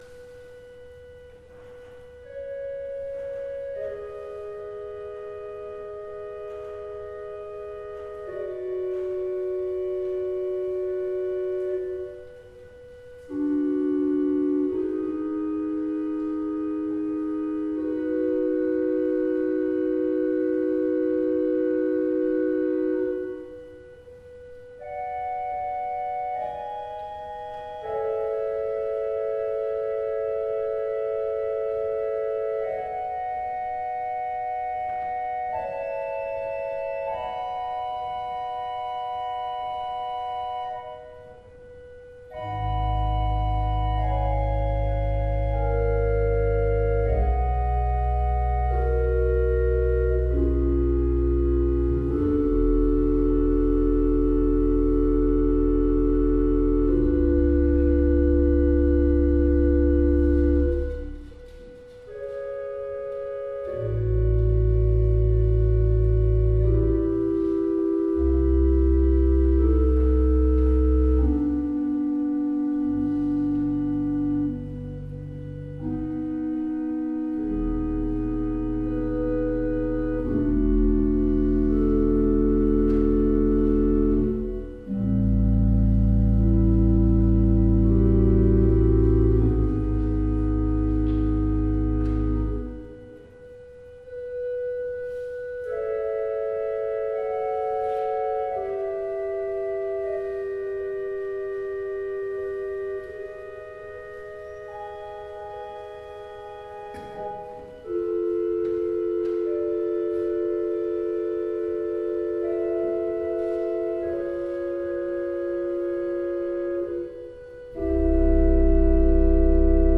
Ĺlands orgelfestival 2014 - inspelningar
Lemland
orgel